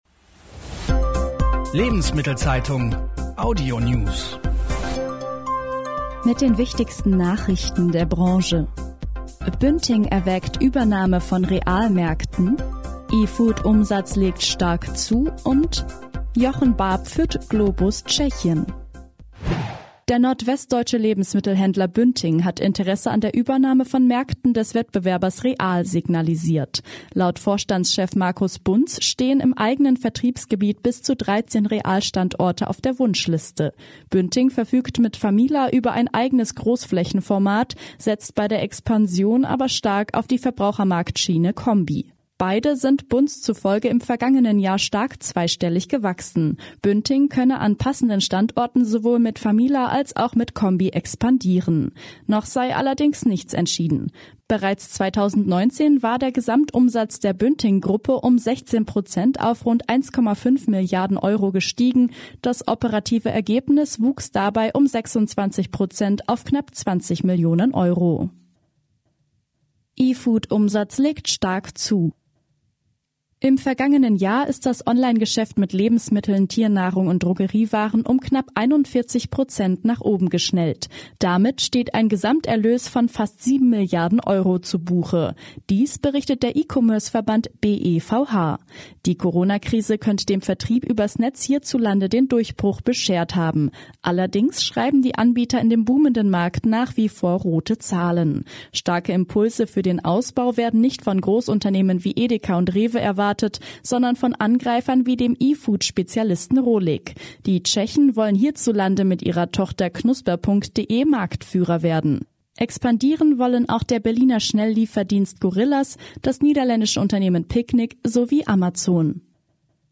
Die wichtigsten Nachrichten aus Handel und Konsumgüterwirtschaft zum Hören